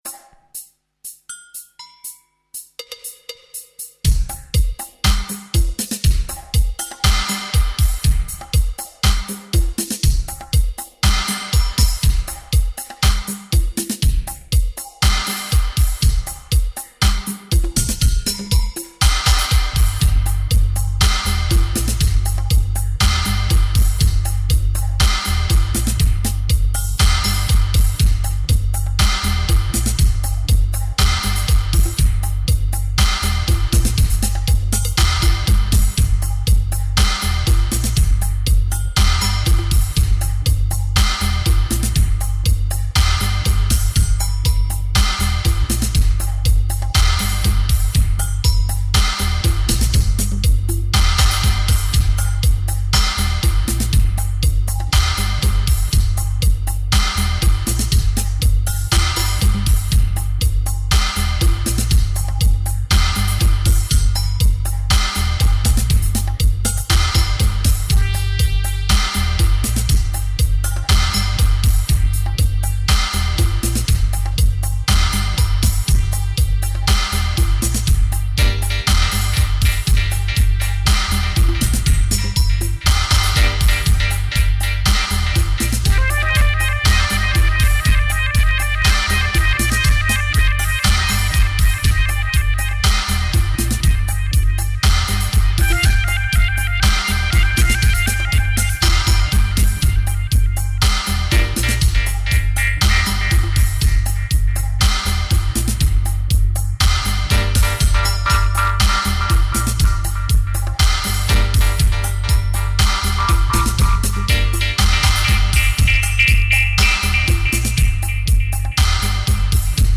Dubplate version